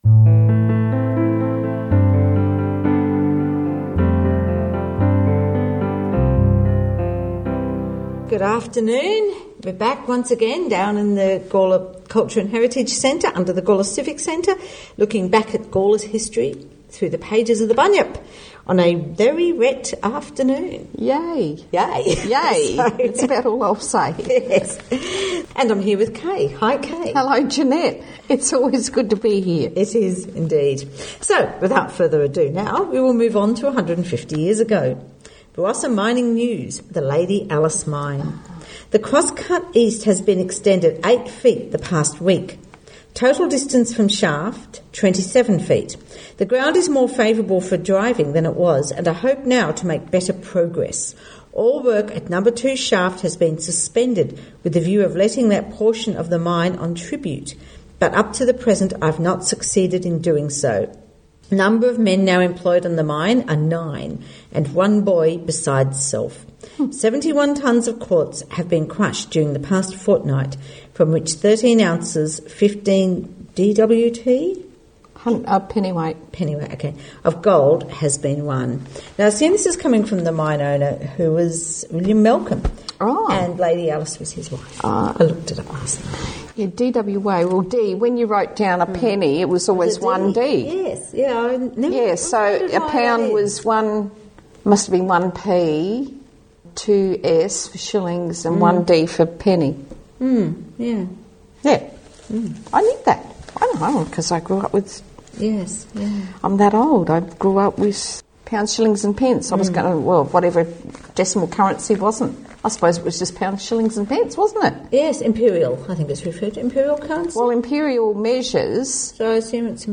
Opening and closing music taken from A Tribute to Robbie Burns by Dougie Mathieson and Mags Macfarlane